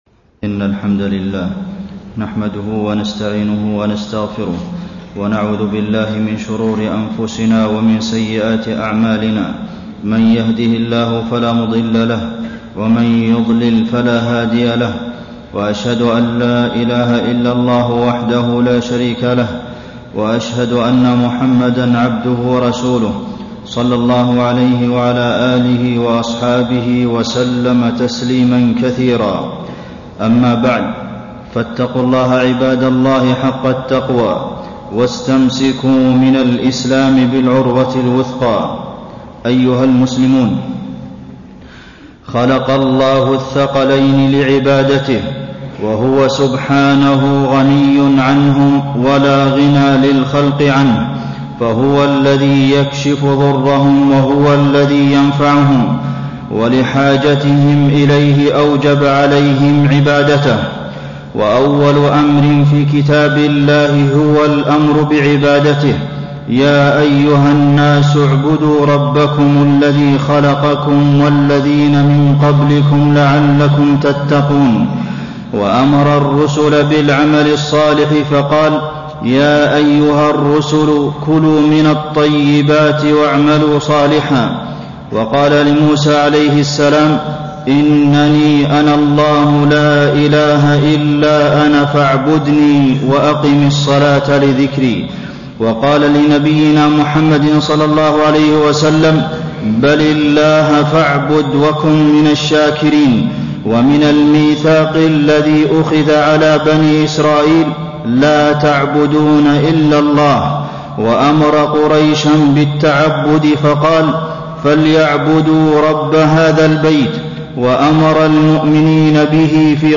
تاريخ النشر ١٦ شعبان ١٤٣٣ هـ المكان: المسجد النبوي الشيخ: فضيلة الشيخ د. عبدالمحسن بن محمد القاسم فضيلة الشيخ د. عبدالمحسن بن محمد القاسم رمضان عبر ونفحات